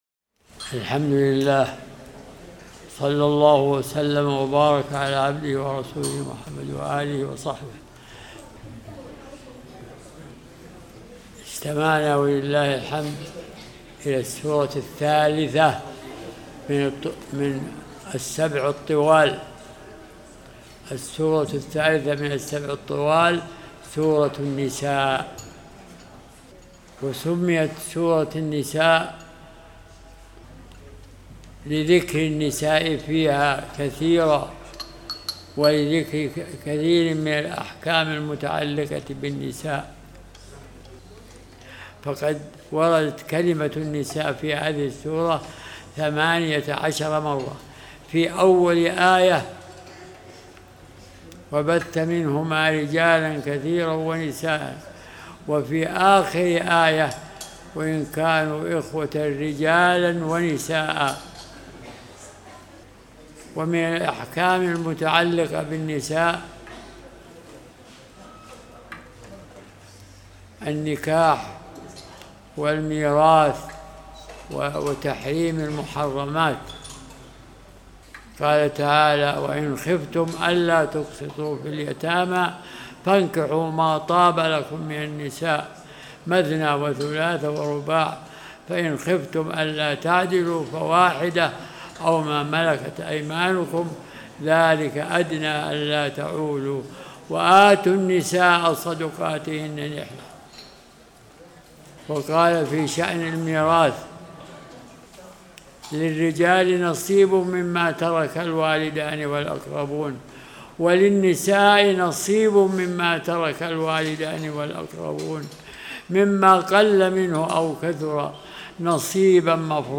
(44) فوائد مستنبطة من سور القرآن - سورتي النساء والمائدة (استراحة صلاة القيام في رمضان 1447)